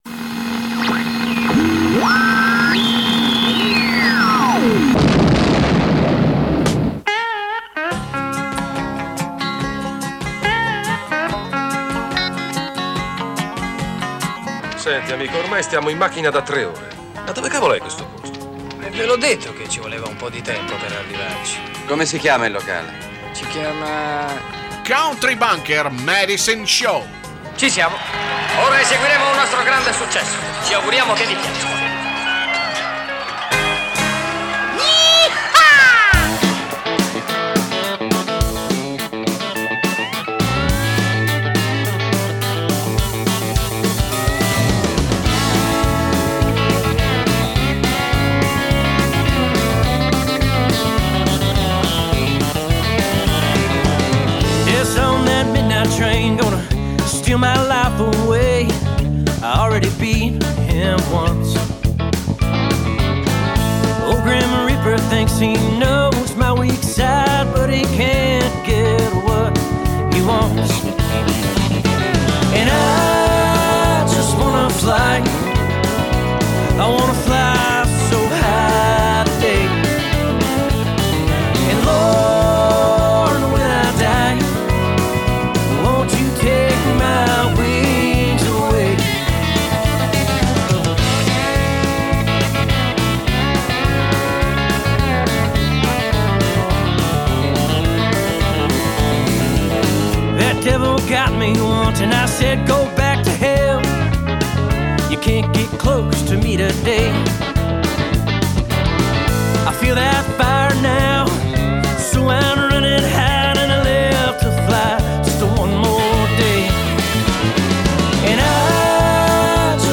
Both kind of music: Country&Western